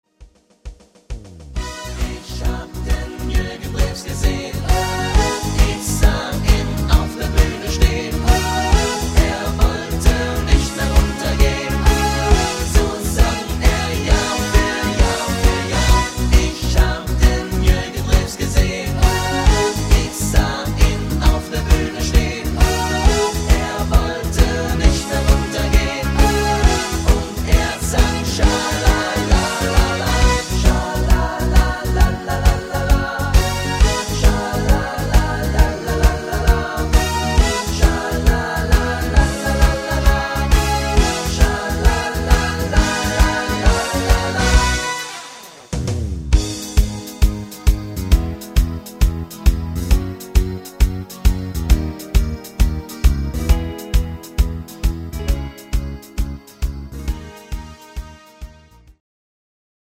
Rhythmus  Rock Shuffle
Art  Deutsch, Mallorca-Songs, Party Hits, Popschlager